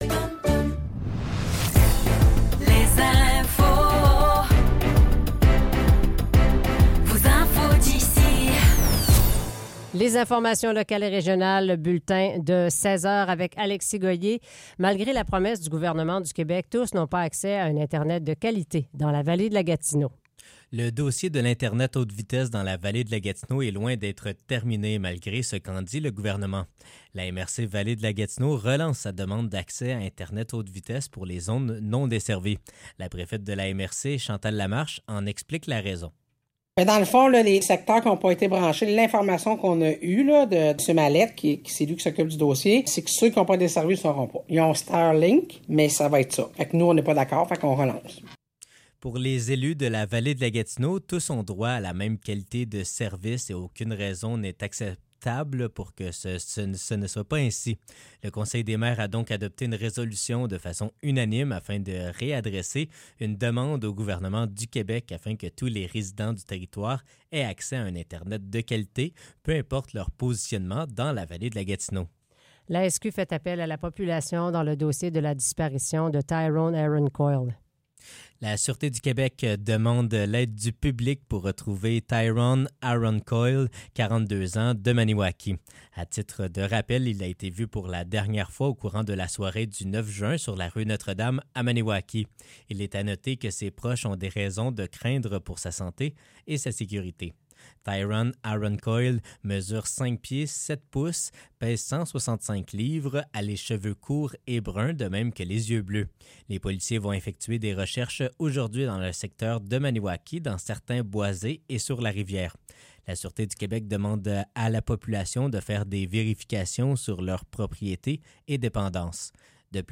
Nouvelles locales - 10 juillet 2024 - 16 h